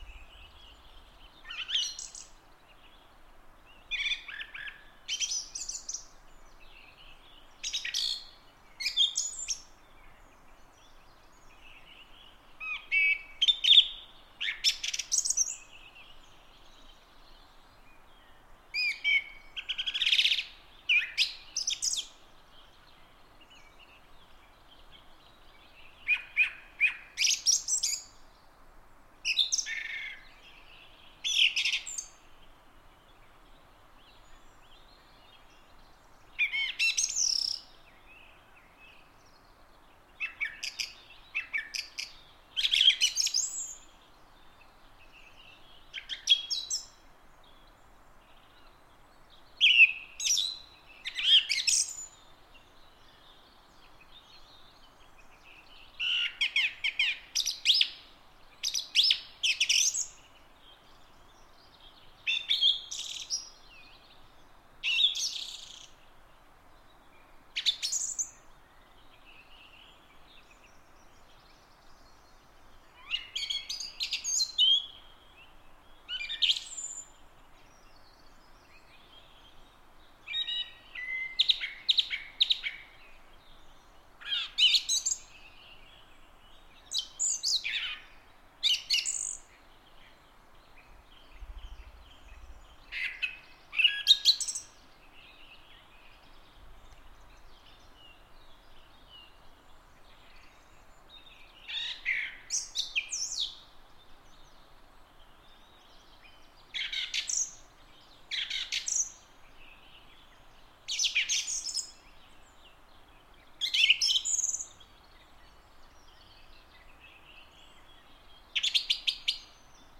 Kuuntele: Laulurastas tekee toistelusta taidetta
Laulurastaan (Turdus philomelos) jankuttava laulu kuuluu kevään ja alkukesän iltoihin kuusikossa.